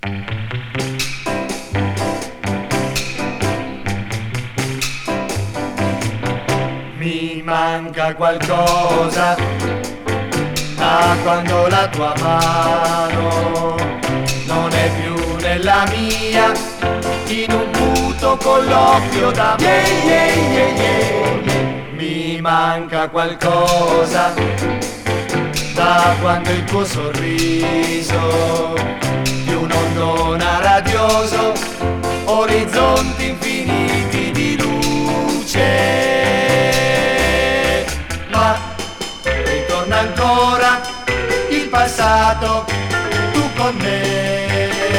Pop, Vocal　USA　12inchレコード　33rpm　Mono